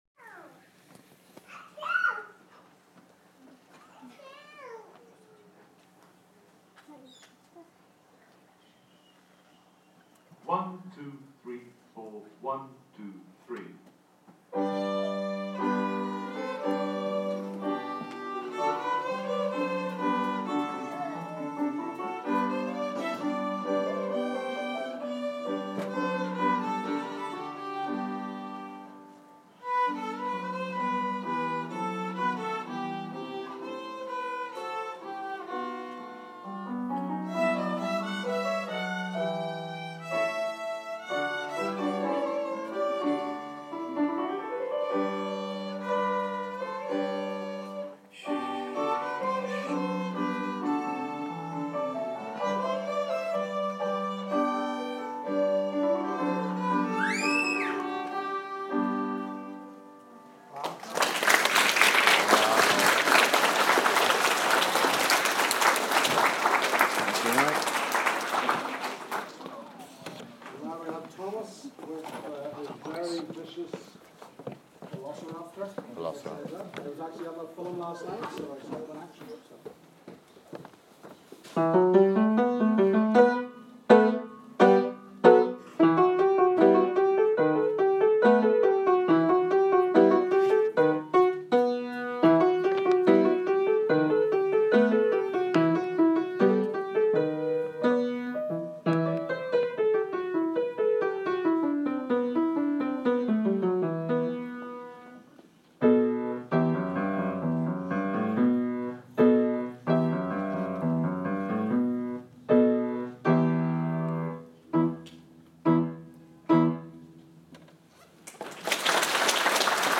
violinists and piano at leavers